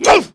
wrench_alt_fire1.wav